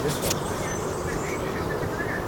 Observação BirdNET - Chamariz - 2022-05-01 09:35:16
Chamariz observado com o BirdNET app. 2022-05-01 09:35:16 em Lisboa